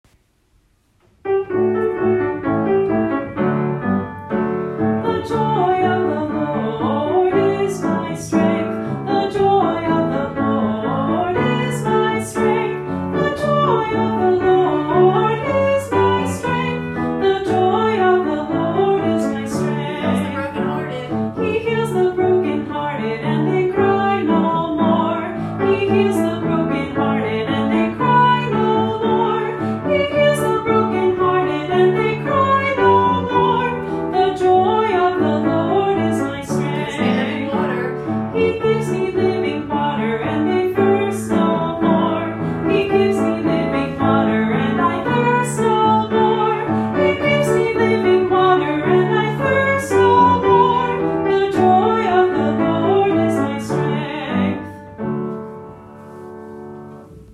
Scripture Songs